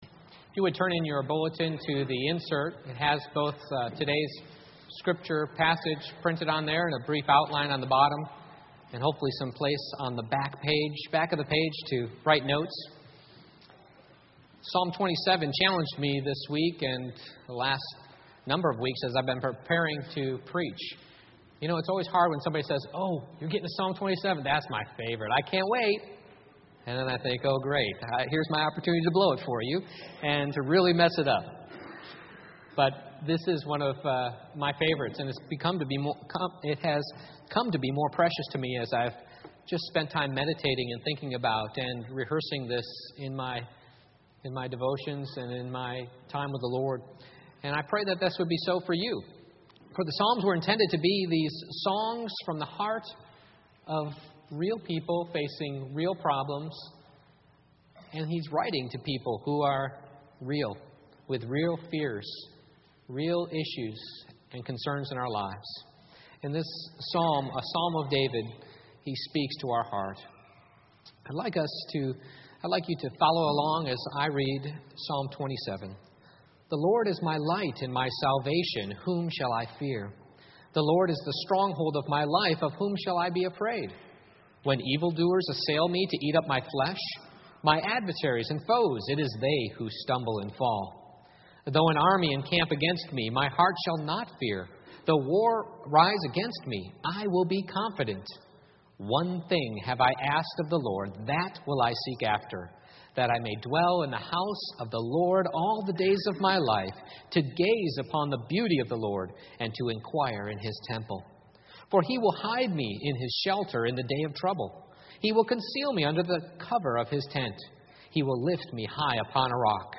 Psalm 27:1-14 Service Type: Morning Worship How to Respond to the Circumstances That Cause Fear in Our Lives